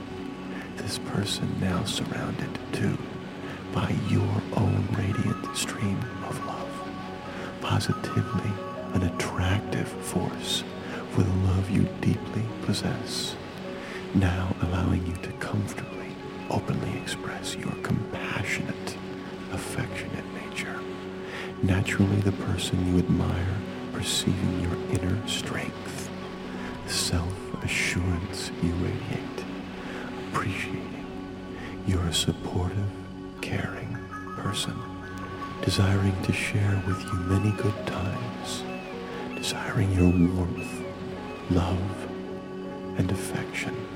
The Get Love Hypnosis session with Mind Series can help the listener achieve it. It helps to remove the negative emotions and conversations causing havoc inside your mind and replace them with positive emotions.